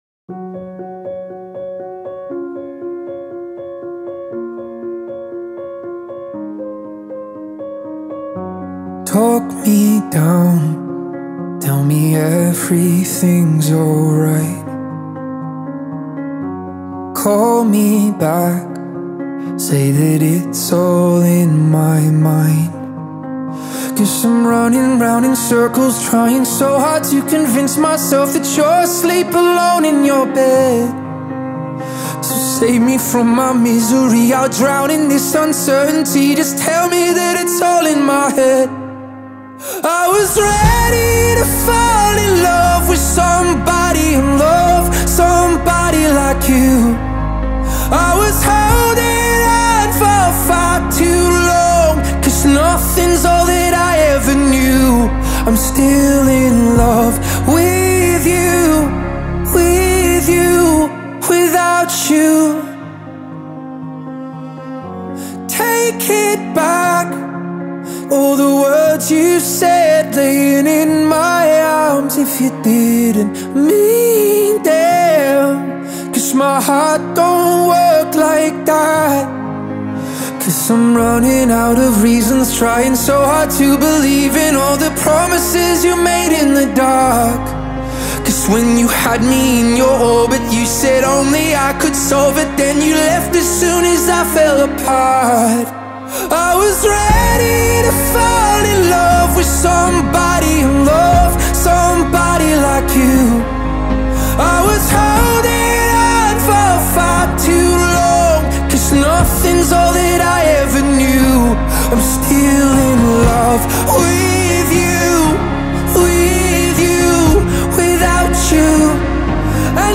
این ترک در سبک پاپ خونده شده